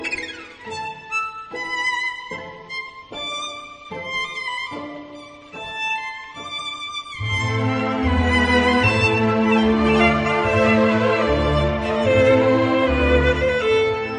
Garage Synth